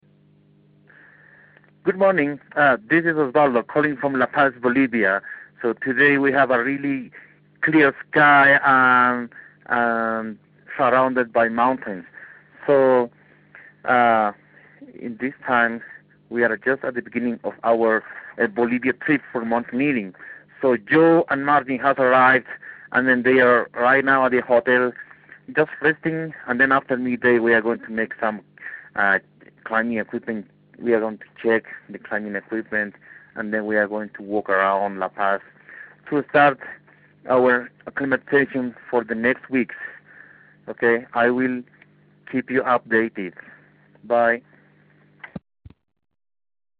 Bolivia Expedition Dispatch